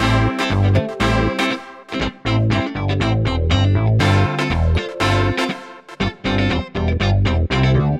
29 Backing PT4.wav